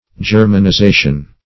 \Ger`man*i*za"tion\
germanization.mp3